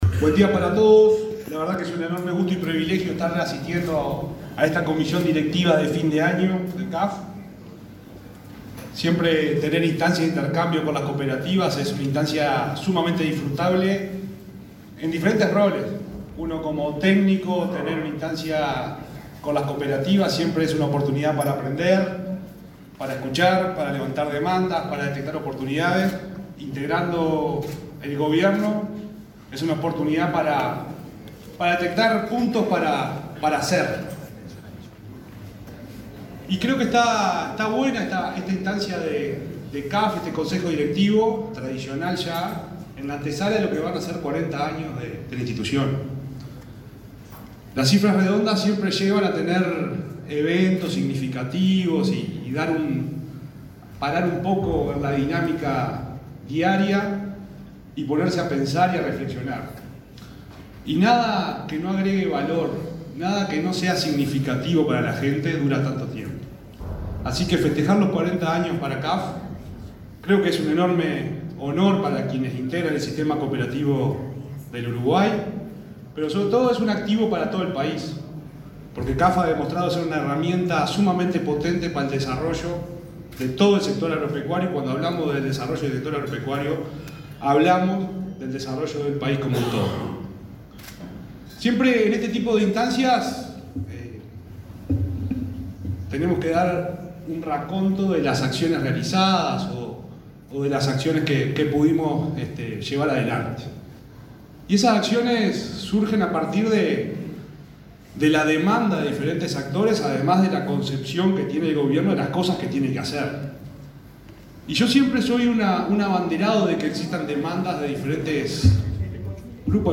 Palabras del subsecretario de Ganadería, Juan Ignacio Buffa
El subsecretario de Ganadería, Juan Ignacio Buffa, se expresó, este martes 12 en Soriano, en la apertura del encuentro de Cooperativas Agrarias